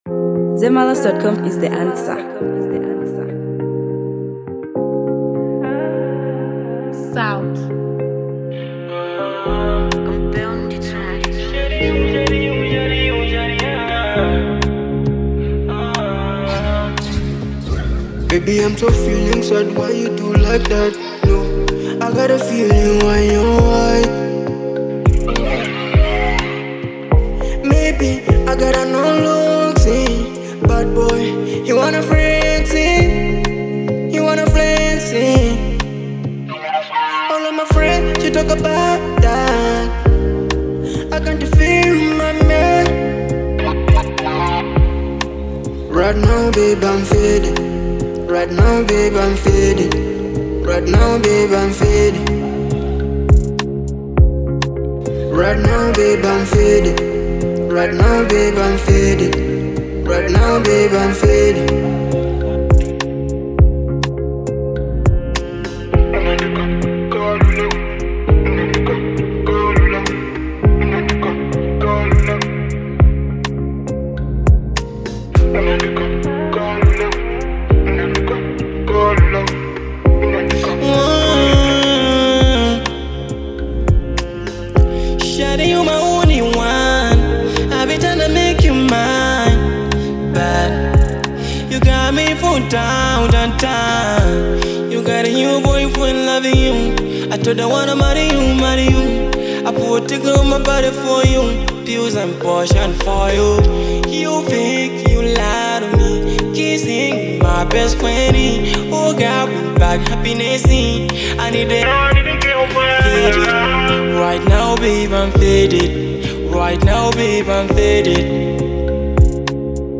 Afrobeats